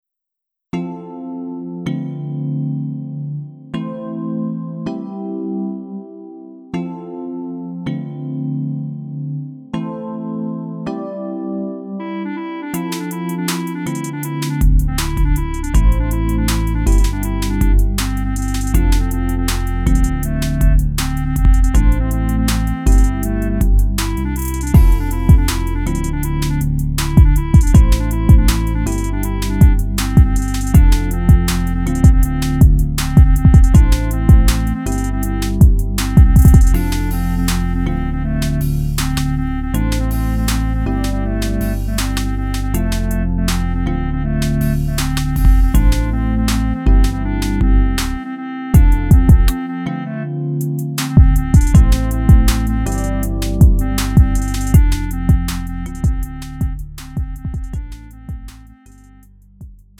음정 -1키 3:15
장르 가요 구분 Lite MR
Lite MR은 저렴한 가격에 간단한 연습이나 취미용으로 활용할 수 있는 가벼운 반주입니다.